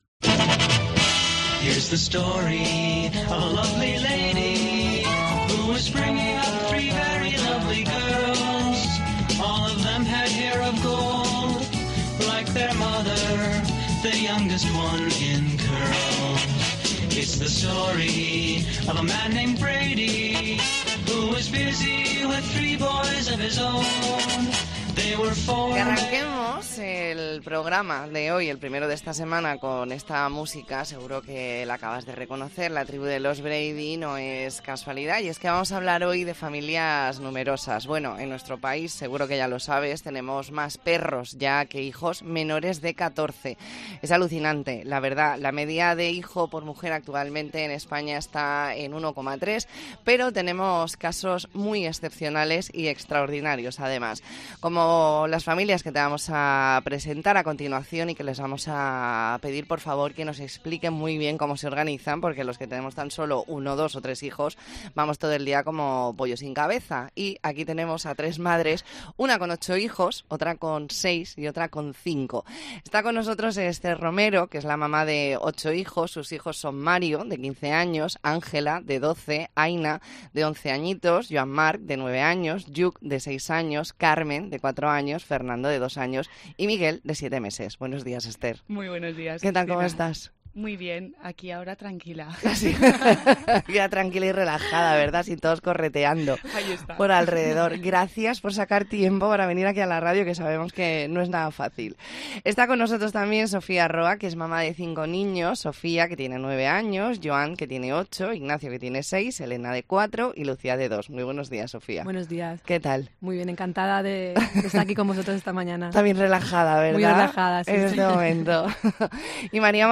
Entrevista en La Mañana en COPE Más Mallorca, lunes 30 de octubre de 2023.